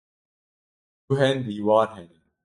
Pronounced as (IPA) /diːˈwɑːɾ/